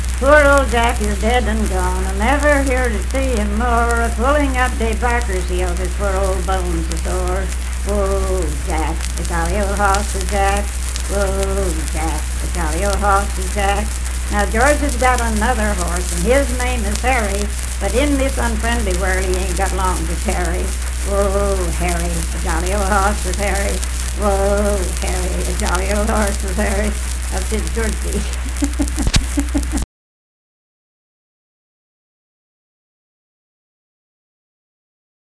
Unaccompanied vocal music performance
Verse-refrain 2 (6w/R).
Voice (sung)